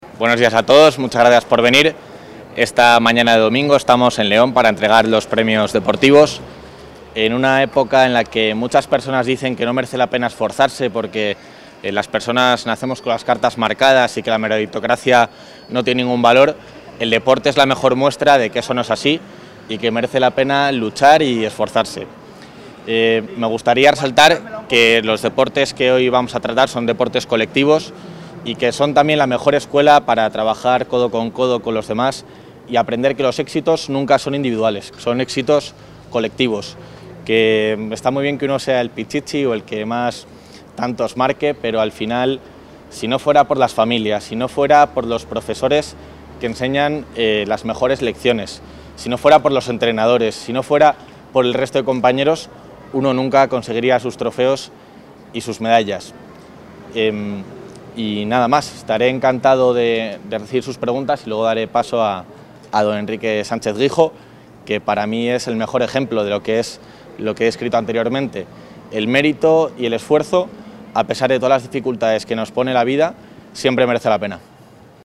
Material audiovisual de la participación del vicepresidente de la Junta en la entrega de los trofeos del Campeonato de Castilla y León en Edad Escolar
Intervención del vicepresidente de la Junta.